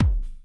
2 Harsh Realm Kick High.wav